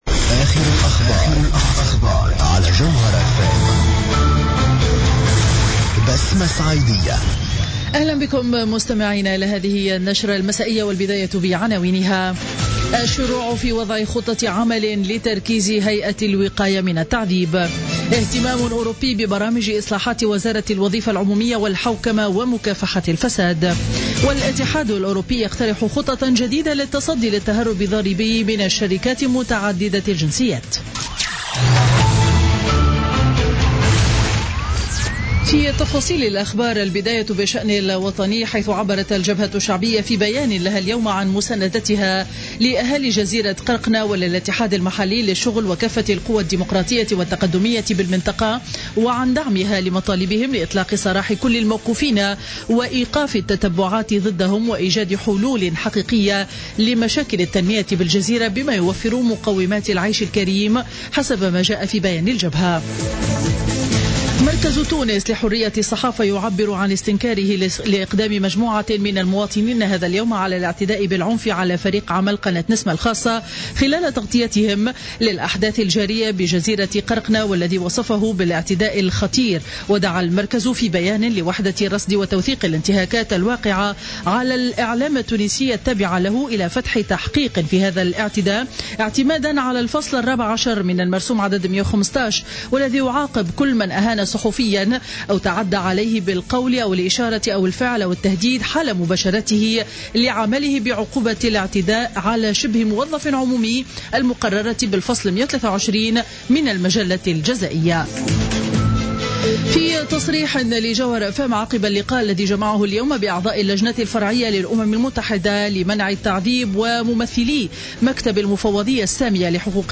نشرة أخبار السابعة مساء ليوم الثلاثاء 12 أفريل 2016